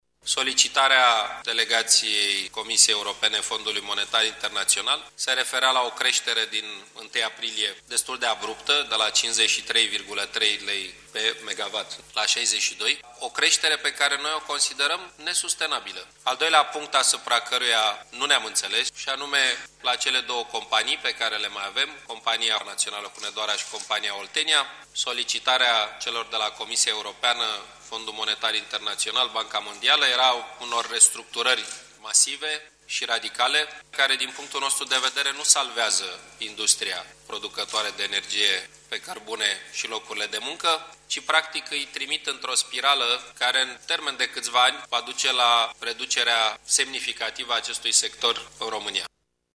Premierul Victor Ponta a explicat că această liberalizare ar însemna creşterea tarifelor la gaze, atât pentru populaţie, cât şi pentru agenţii economici: